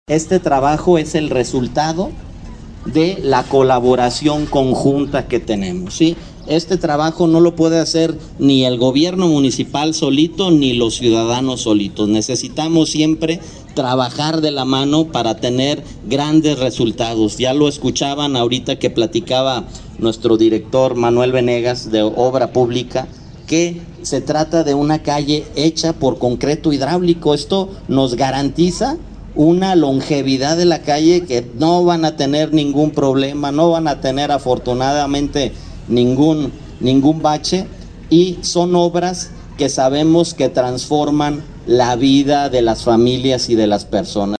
AudioBoletines
Rodolfo Gómez Cervantes, presidente municipla interino